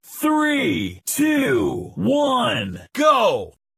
Decompte.mp3